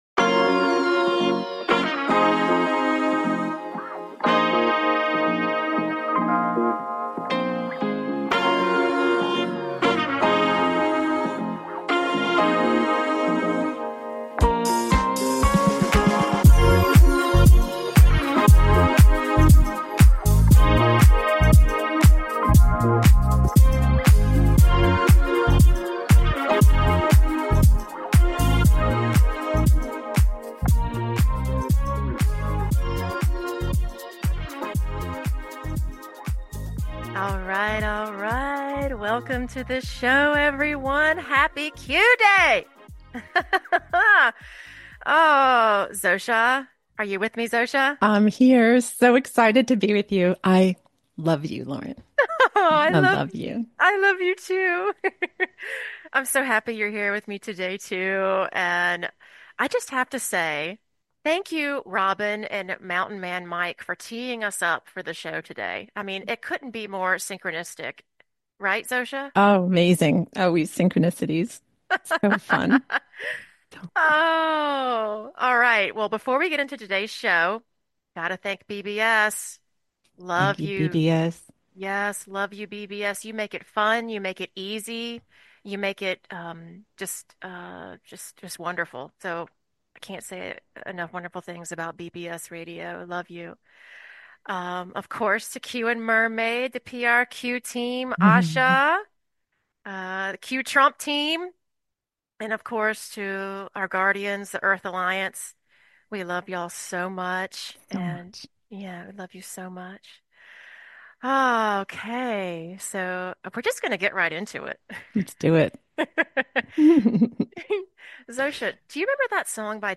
Talk Show Episode, Audio Podcast
Call-in Listeners